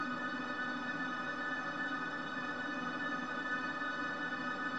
• Creepy background pad 100bpm.wav
Creepy_background_pad_100bpm__n4u.wav